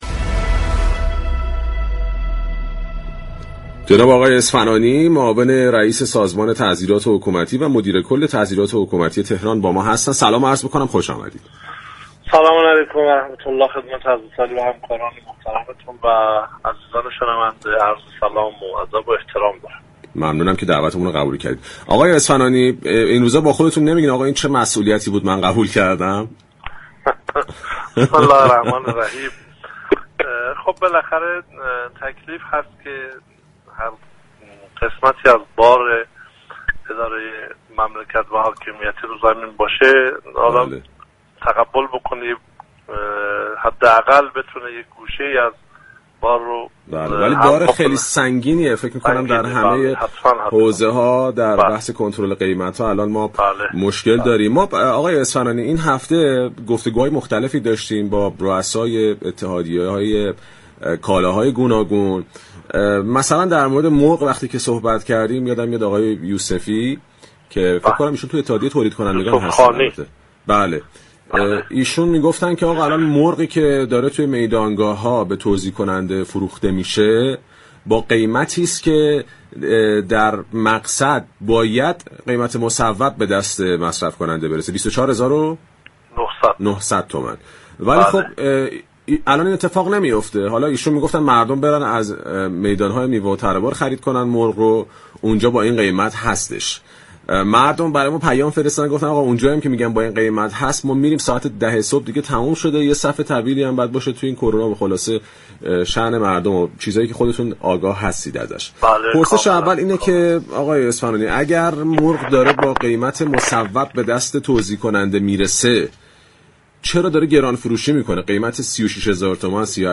محمدعلی اسفنانی مدیركل سازمان تعزیرات حكومتی استان تهران در گفتگو با برنامه بازار تهران با اشاره به اینكه تامین مرغ به دلایل مشكلات ساختاری به بحثی پیچیده تبدیل شده است گفت: مصرف متعارف مرغ در تهران 1200 تا 1500 تن در روز است . 96 درصد مرغ مورد نیاز استان تهران از استان های مجاور تامین می شود یعنی سازوكار تامین مرغ برای این استان از اول اشتباه بوده است.